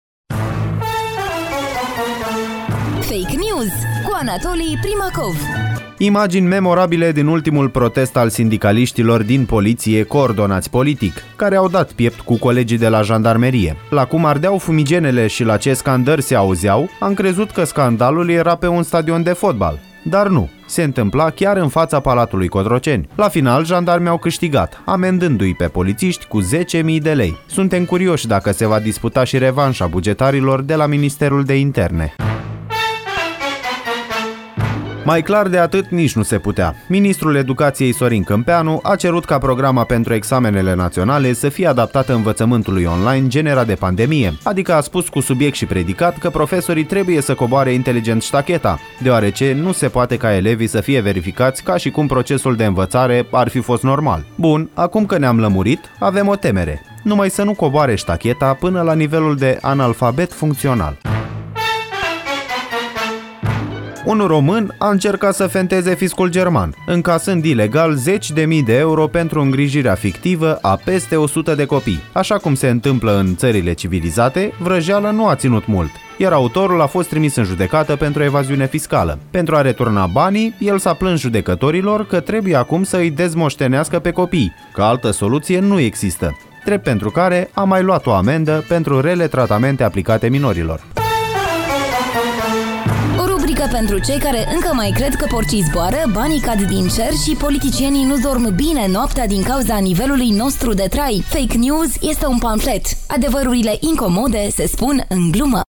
Genul programului: pamflet.